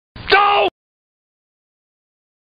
Homer Simpson Doh sound effect